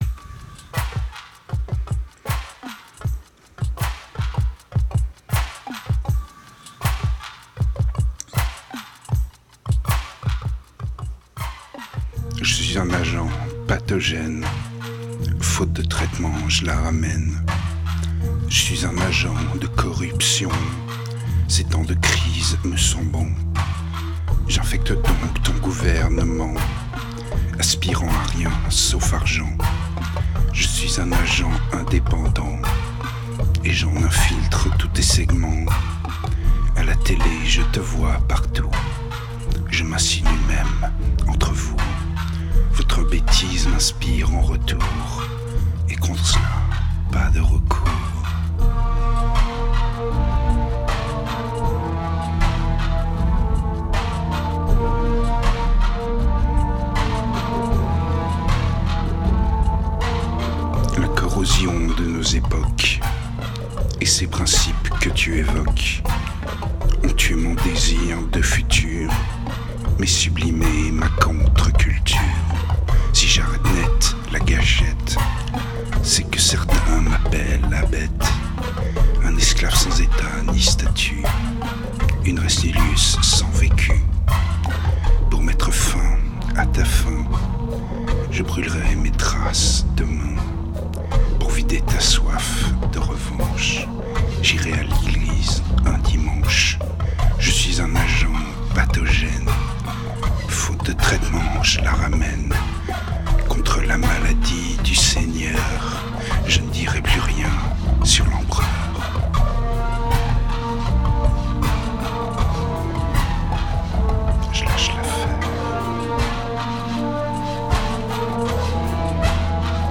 1917📈 - -20%🤔 - 79BPM🔊 - 2009-05-27📅 - -177🌟